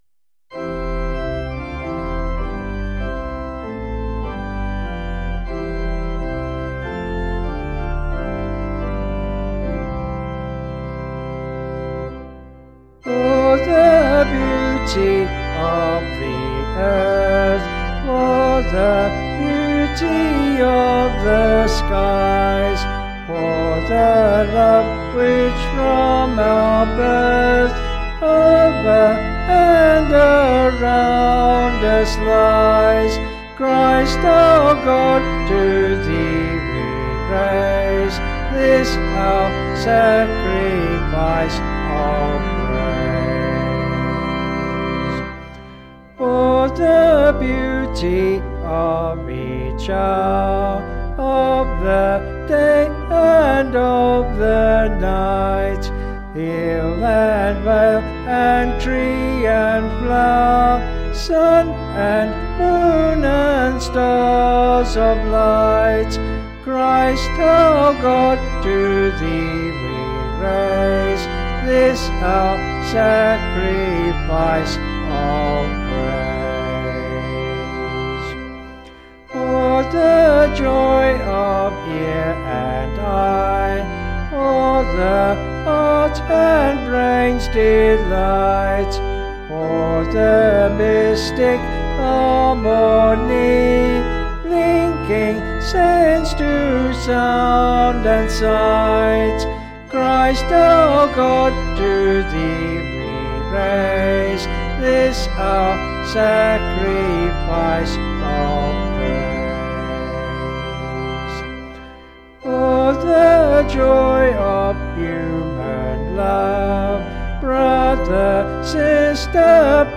(BH)   5/Bb
Vocals and Organ   263.6kb Sung Lyrics